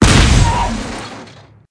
Expl04.wav